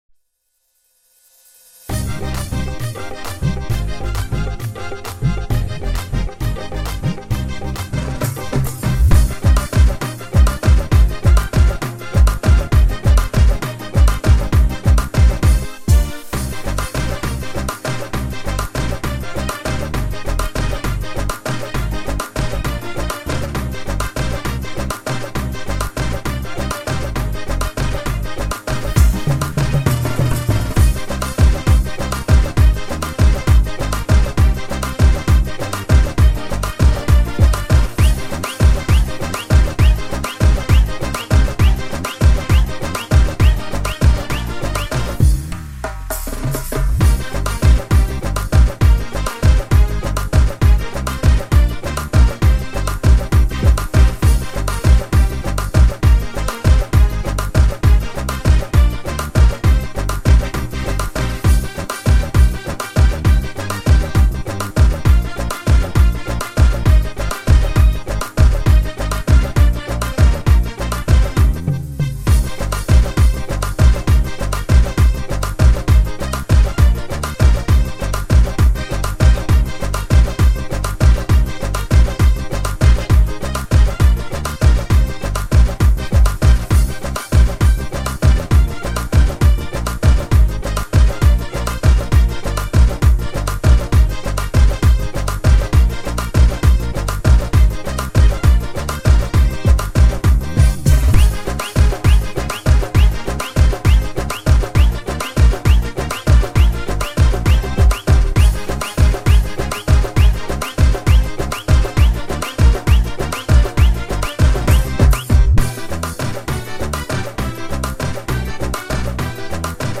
Papare Full Fun Dance